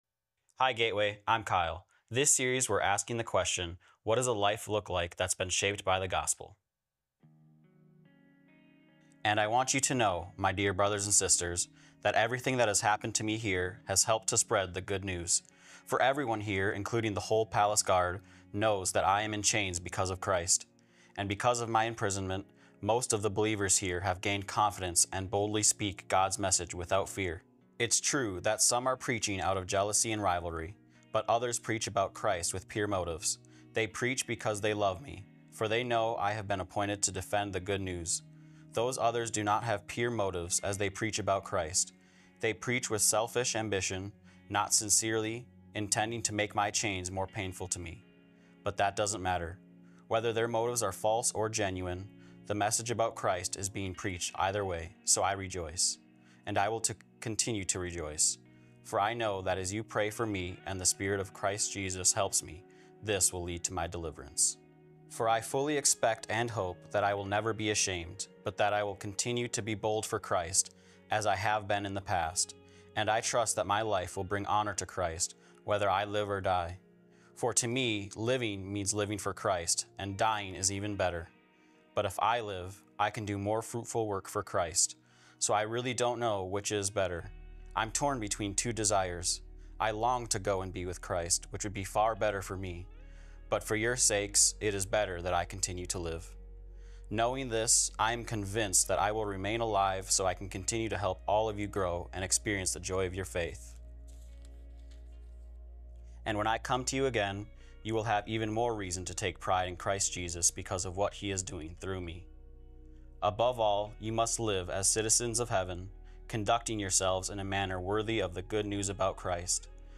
Gospel-First-Priorities-Sermon-5.4.25.m4a